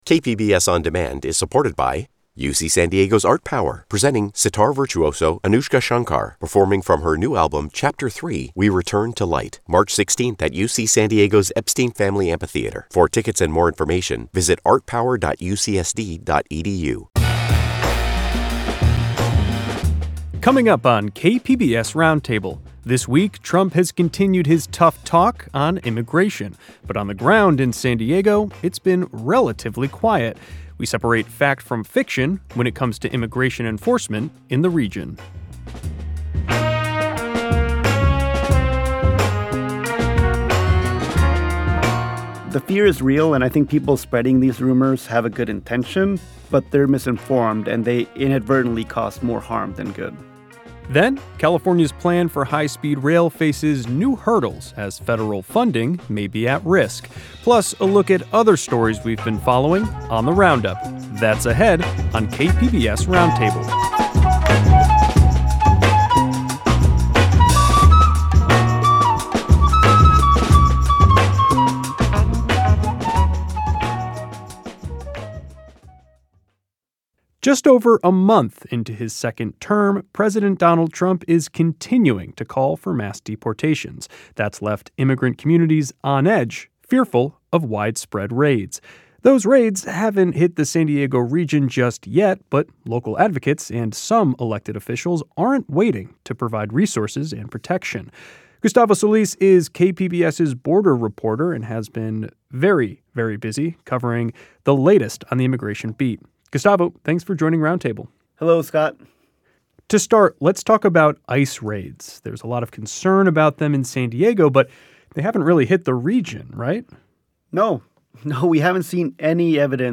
Join us for a weekly discussion with reporters, adding depth and context to the headlines driving the news in the San Diego region.